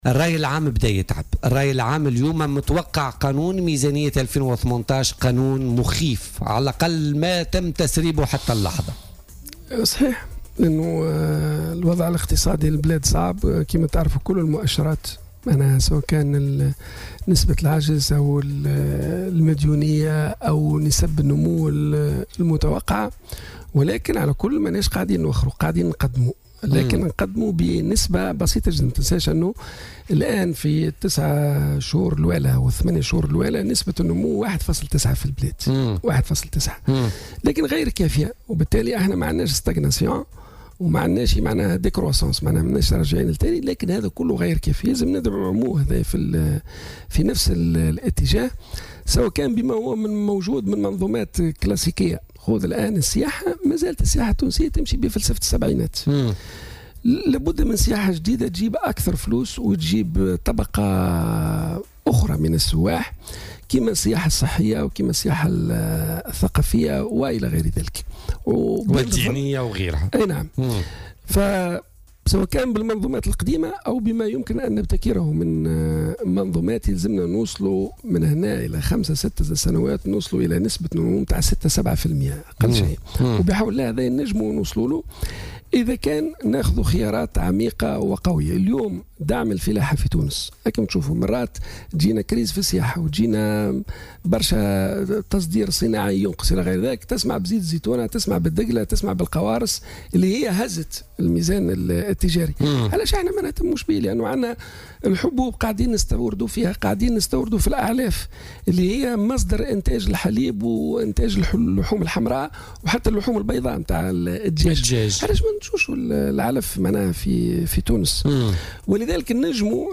ودعا المكي، ضيف بوليتيكا، اليوم الأربعاء، إلى ضرورة تغيير المنوال التنموي في البلاد وفك ارتباطه بالسياحة أو تطوير هذا القطاع وتعصيره ليستهدف شرائح معينة من السياح، بالتوازي مع دعم قطاعات أخرى وعلى رأسها الفلاحة والتي أثبتت قدرتها على إنقاذ الميزان التجاري التونسي بفضل ارتفاع صادرات زيت الزيتون والقوارص والتمور، مشددا على أهمية دعم الفلاحين والتوجه نحو زراعة الحلوب الأعلاف لتكون متوفرة لدى منتجي الحليب واللحوم البيضاء والحمراء.